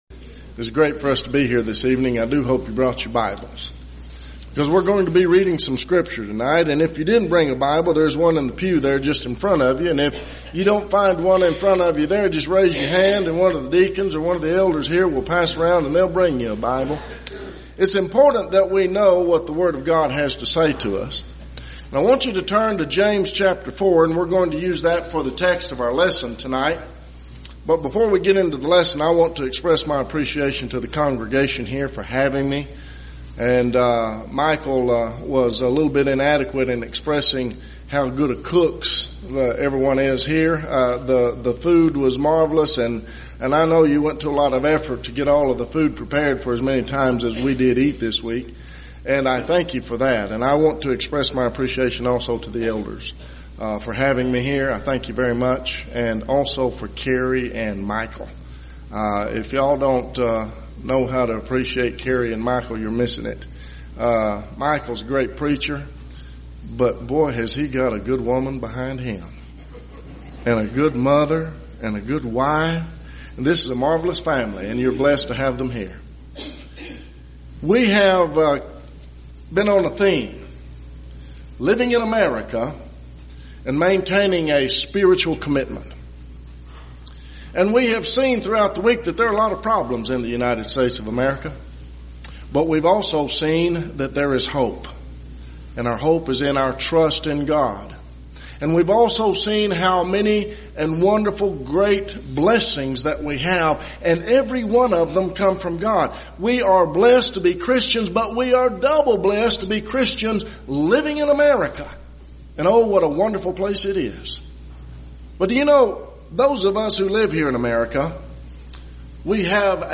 Theme/Title: Spring Gospel Meeting